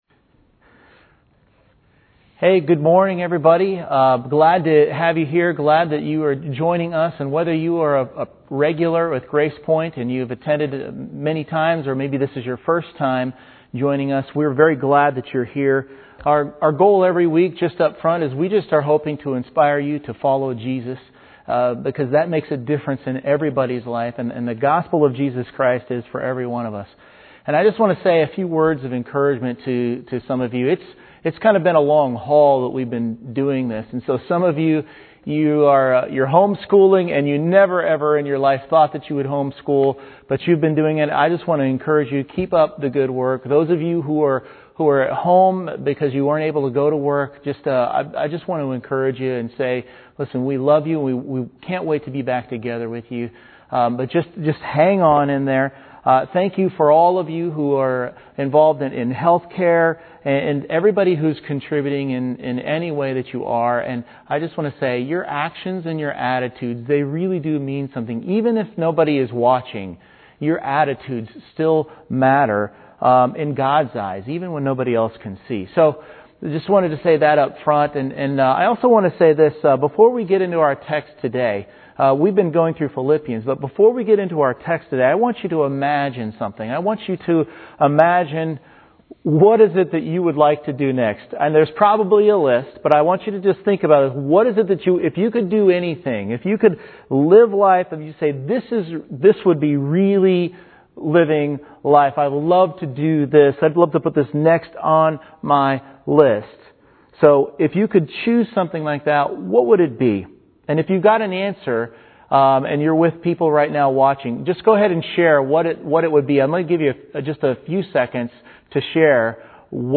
Download Files Sermon Slides Discussion Questions